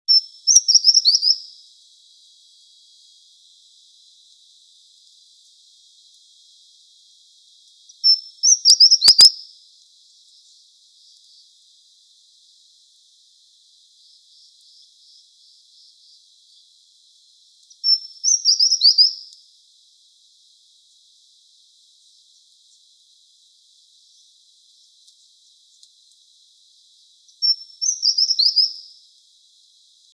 28-1阿里山2011麟胸鷦鷯5.mp3
臺灣鷦眉 Pnoepyga formosana
嘉義縣 阿里山 阿里山
錄音環境 闊葉林
鳥叫
Sennheiser 型號 ME 67